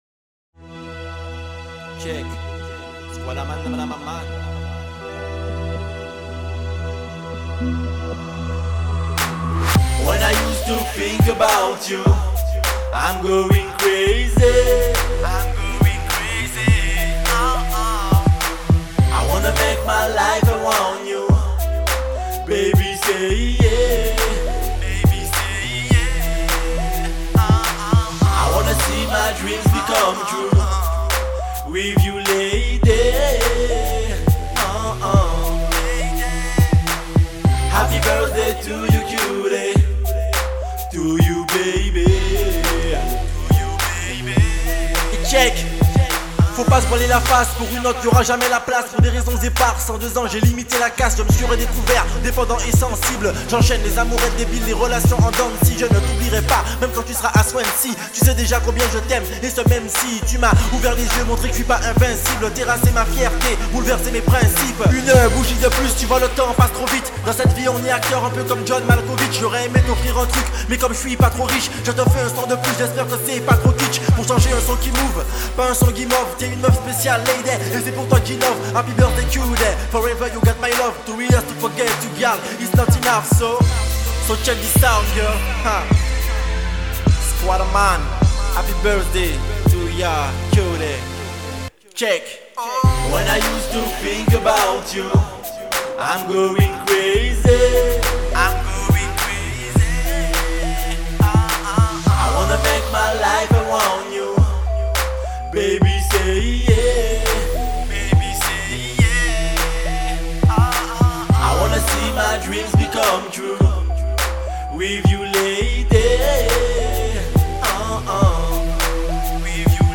Je sais qu'il existe une prédominance électro, house rock..mais voilà .. moi je suis issu de la culture Caricom : reggae rap rnb dance hall..
Et avec mon groupe ..on fait du son dans notre petite chambre..et pis voilà...